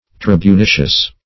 Tribunitious \Trib`u*ni"tious\